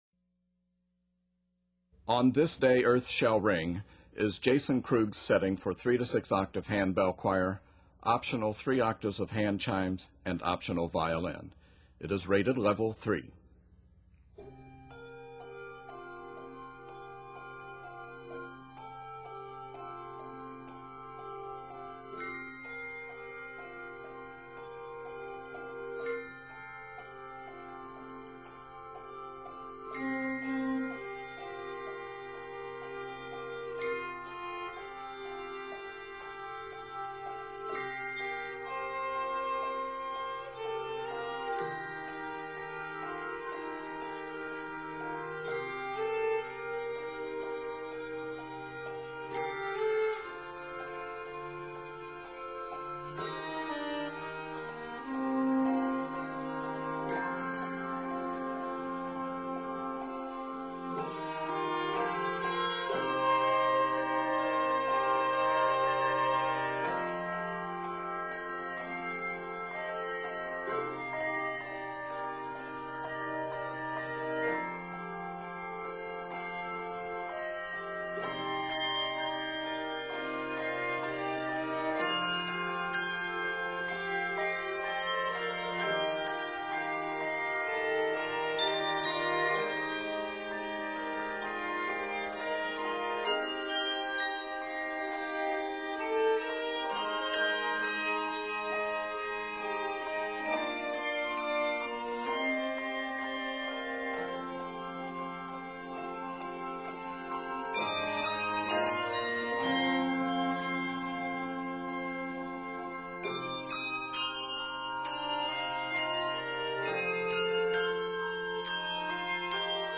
with optional chimes and flowing eighth-note accompaniment
Octaves: 3-6